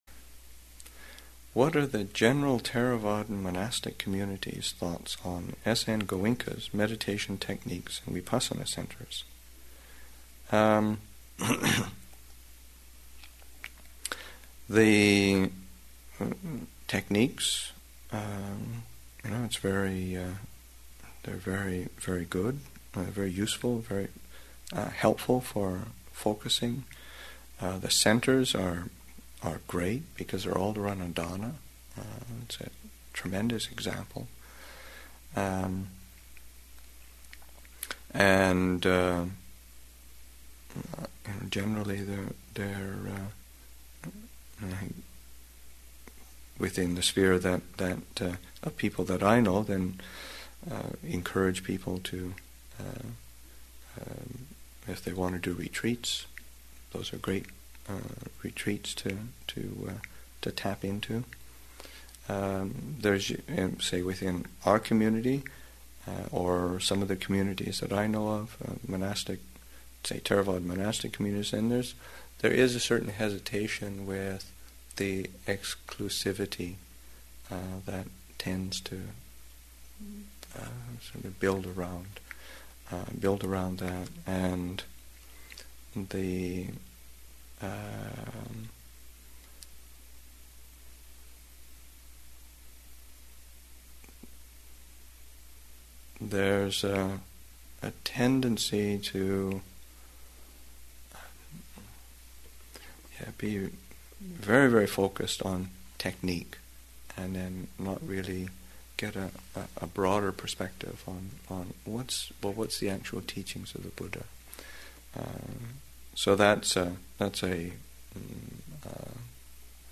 Metta Retreat, Session 3 – Sep. 11, 2008